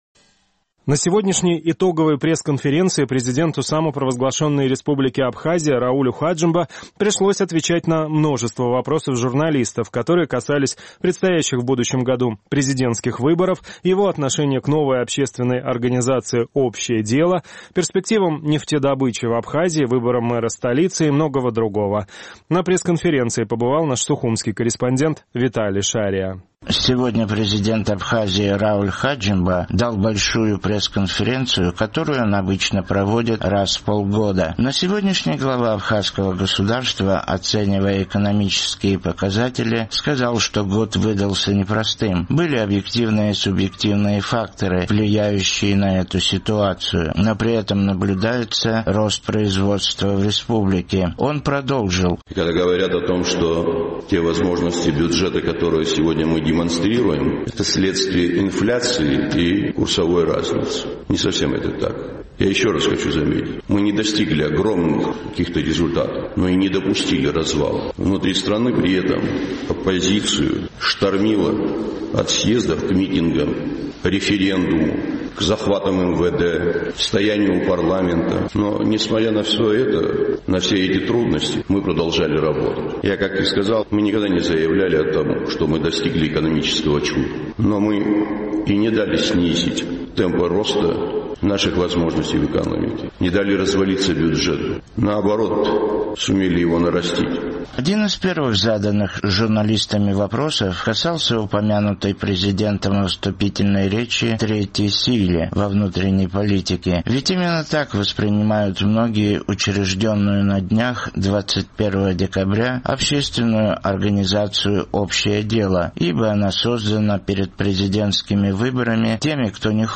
На сегодняшней итоговой пресс-конференции Раулю Хаджимба пришлось отвечать на вопросы журналистов, которые касались предстоящих в будущем году президентских выборов, перспектив нефтедобычи в Абхазии, а также других тем.